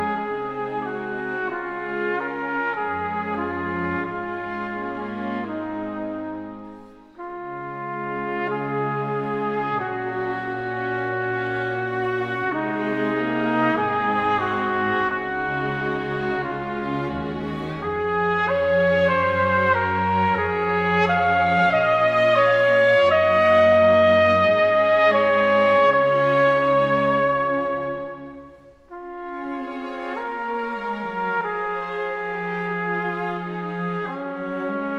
# Classical